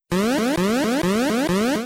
trhq_alarm.wav